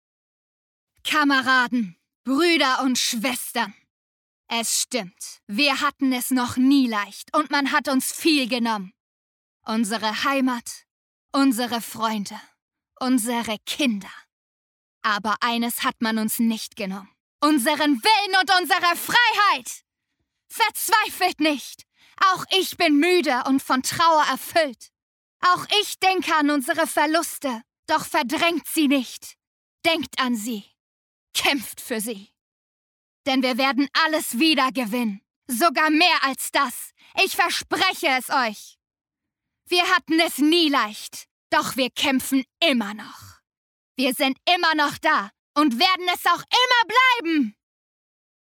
Kraftvolle Entschlossenheit und unerschütterlichen Mut.